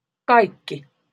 Ääntäminen
IPA: /ˈkɑi.kːi/